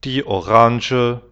Pomerancpomeranč die Orange [oranže]
die-Orange.wav